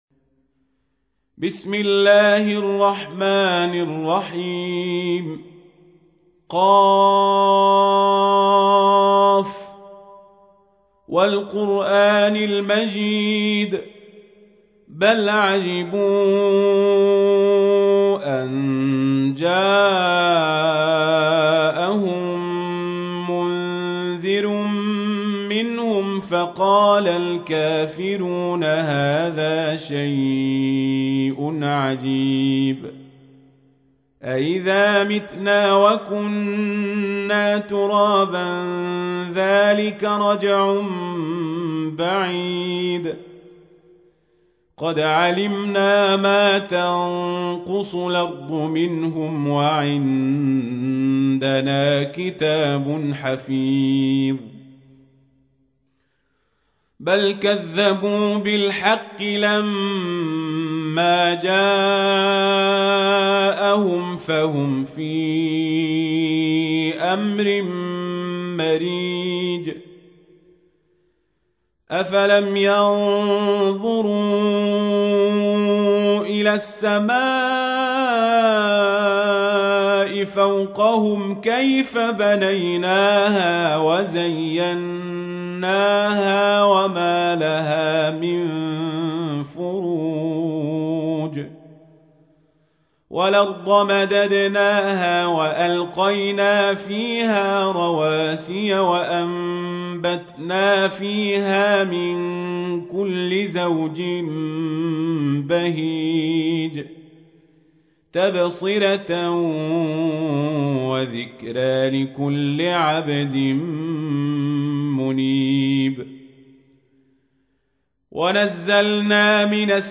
تحميل : 50. سورة ق / القارئ القزابري / القرآن الكريم / موقع يا حسين